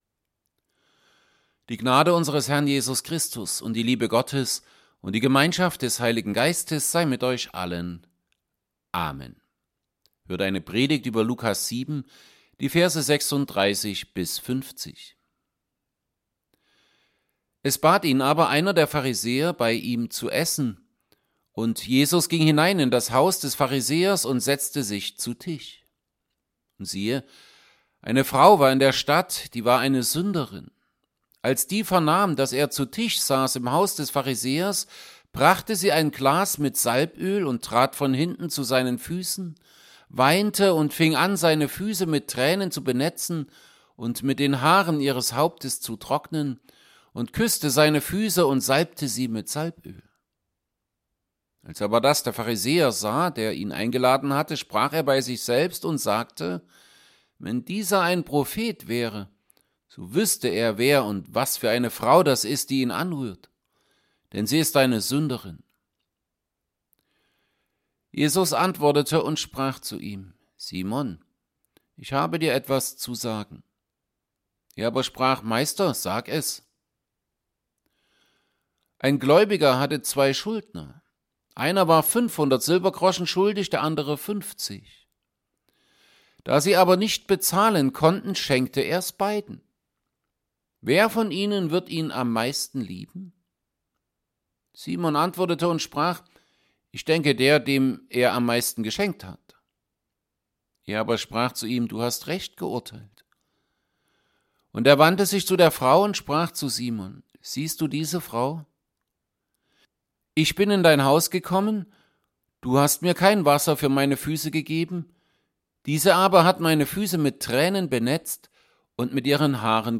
Predigt_zu_Lukas_7_36b50.mp3